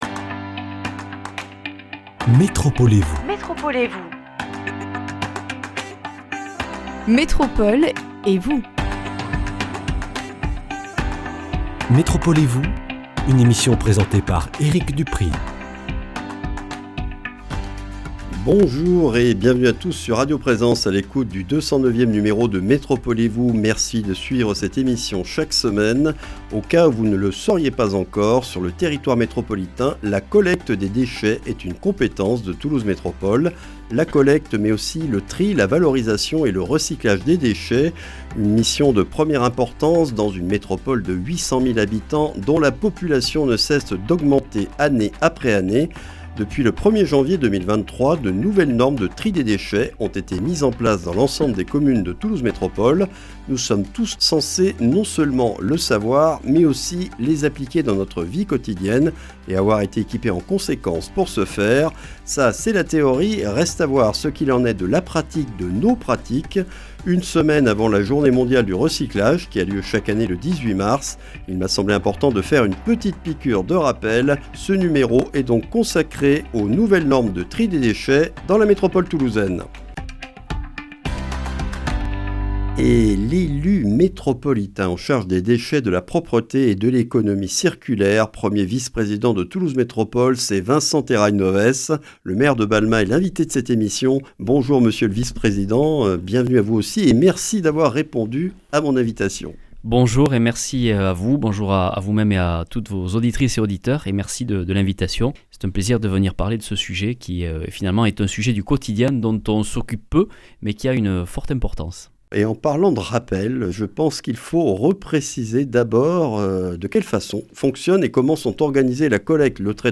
Vincent Terrail-Novès, maire de Balma, 1er vice-président Toulouse Métropole chargé de l’Économie circulaire, des Déchets et de la Propreté, est l’invité de ce numéro. Depuis le 1er janvier 2023, de nouvelles normes de tri des déchets sont en vigueur dans la métropole toulousaine. Présentation de ses évolutions et des prochaines à venir concernant les biodéchets à partir de 2024.